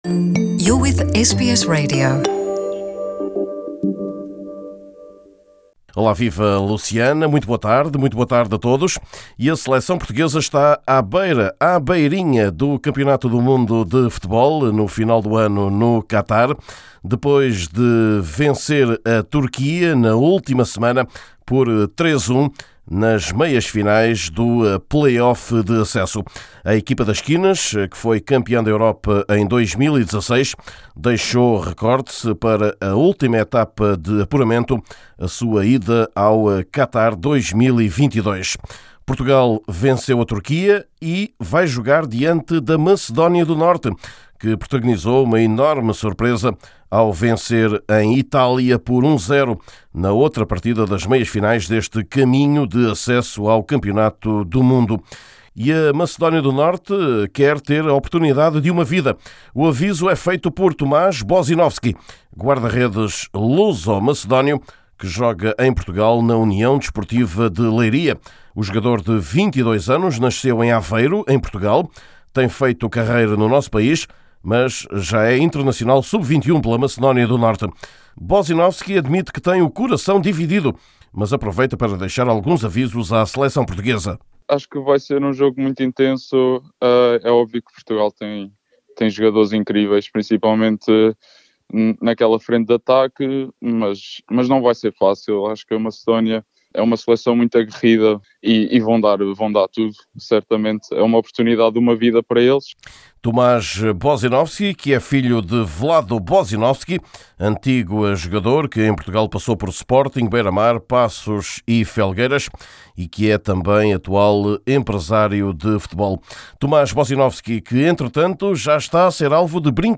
Também neste boletim esportivo, o mercado ou os 40 anos após a primeira vez de Bjorn Borg em Portugal. Entrevista